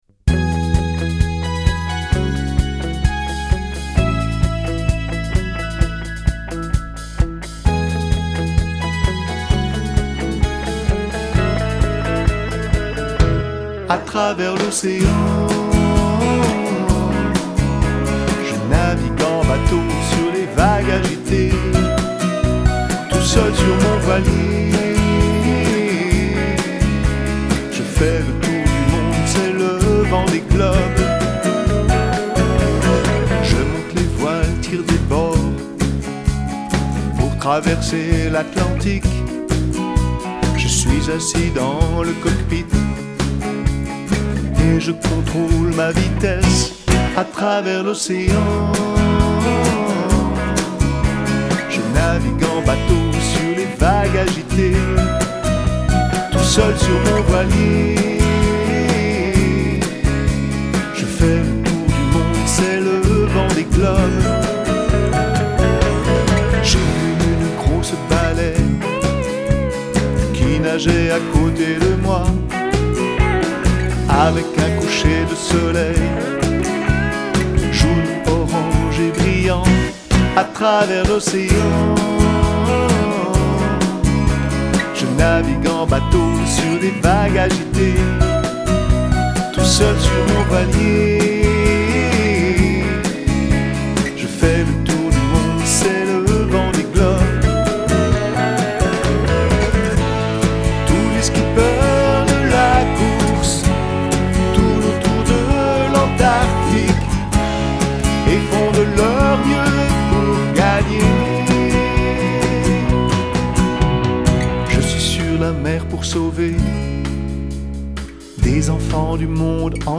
Chant